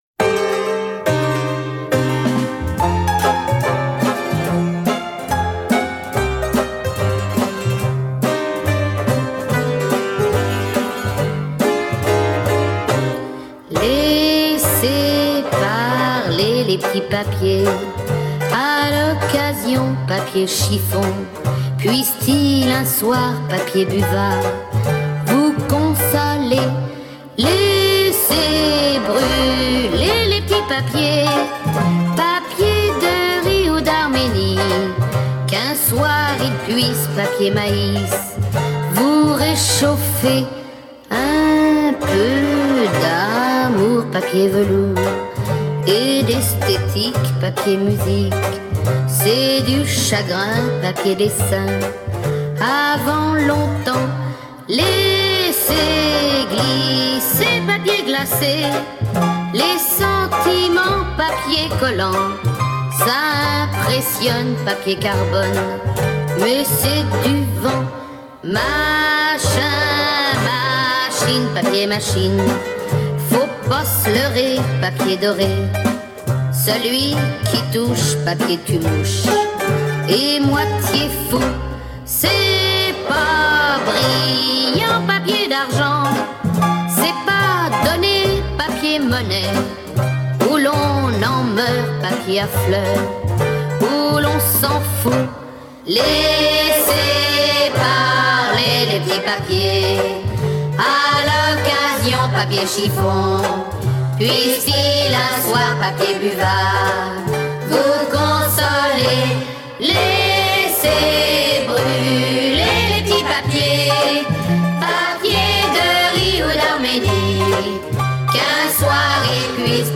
en Cm